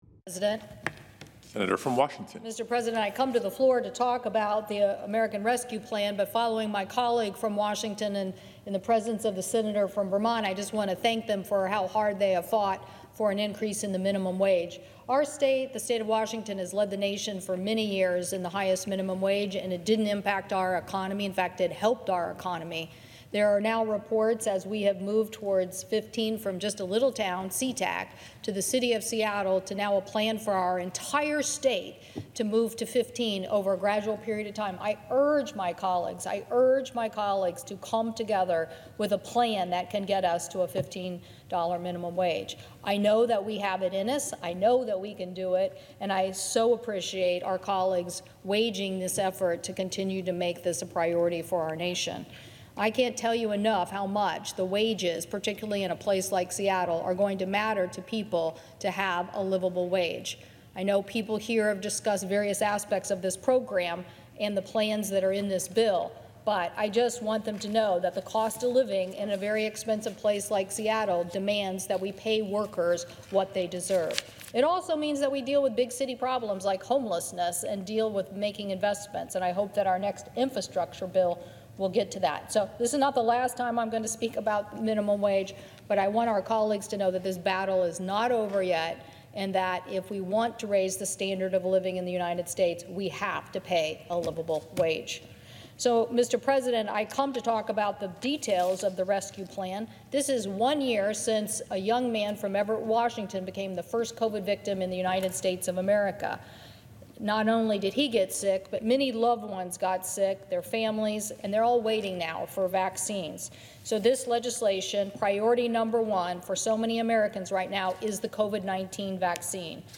WASHINGTON, D.C. – This morning as the U.S. Senate considered President Biden’s $1.9 trillion American Rescue Plan, Senator Maria Cantwell (D-WA) spoke on the Senate floor about the urgent need for this legislation to help Washingtonians and our economy recovery. The bill includes $20 billion for vaccine distribution, $60 billion for small businesses, an extension in unemployment benefits, and additional $1400 stimulus checks for millions of Americans, among other critical relief provisions.